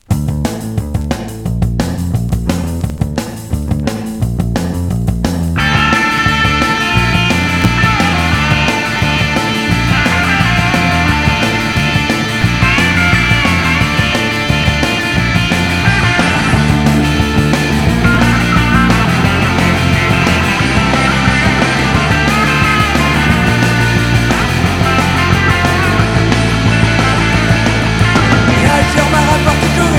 Rock oi